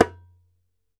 ASHIKO 4 0ER.wav